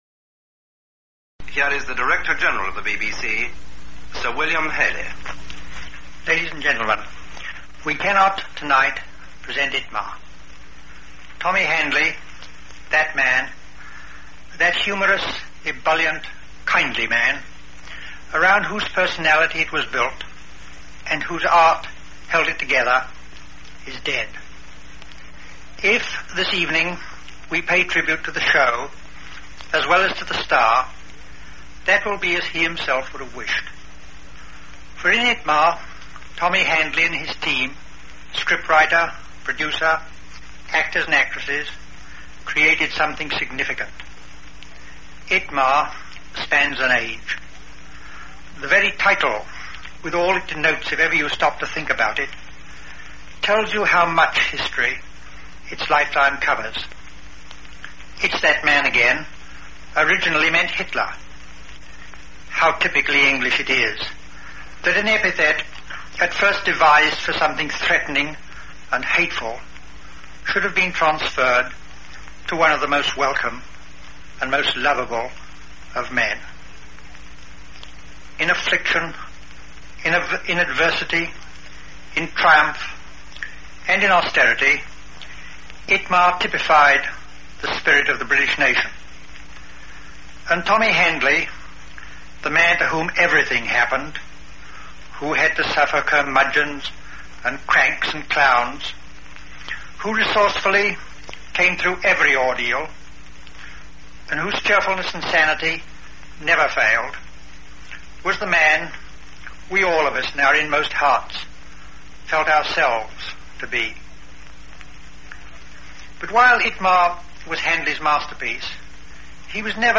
An archive of the radio comedy show ITMA (It's That Man Again!)